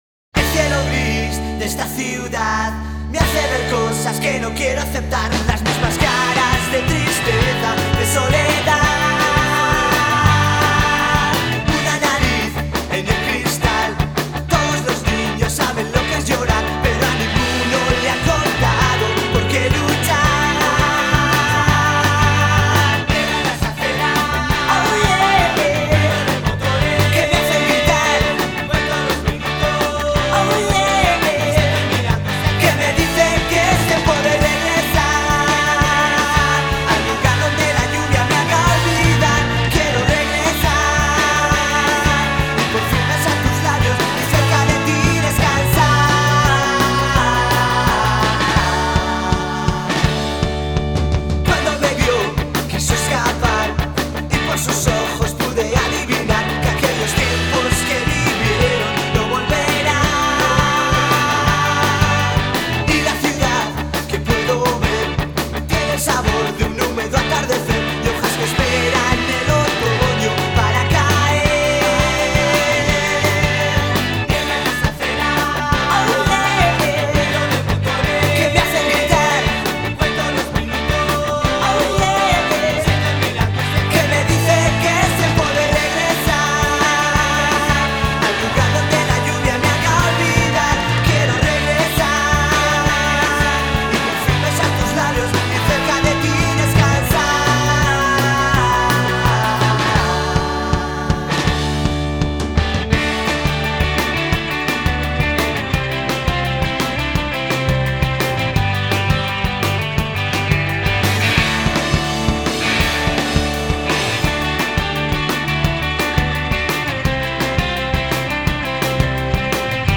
roll out and build up